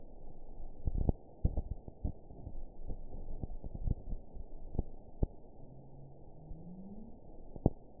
event 912250 date 03/22/22 time 02:08:27 GMT (3 years, 3 months ago) score 7.77 location TSS-AB07 detected by nrw target species NRW annotations +NRW Spectrogram: Frequency (kHz) vs. Time (s) audio not available .wav